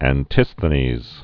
(ăn-tĭsthə-nēz) 444?-371?